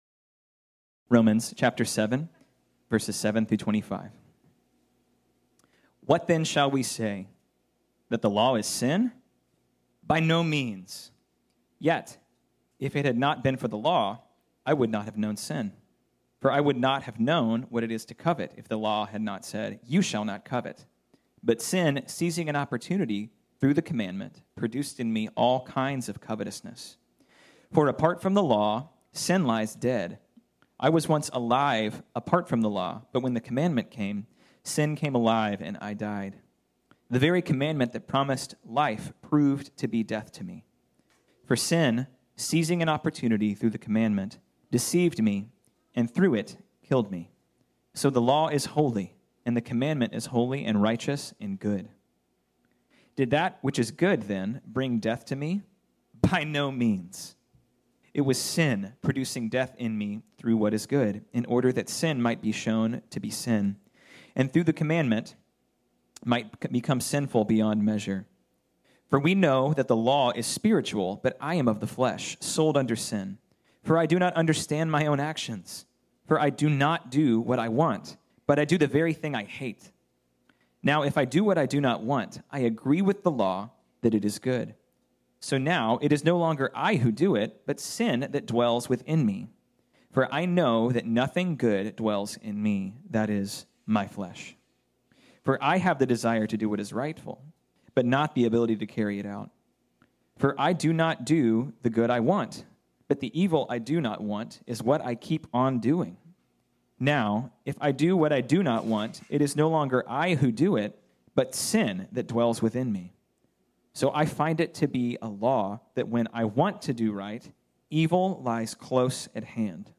This sermon was originally preached on Sunday, April 25, 2021.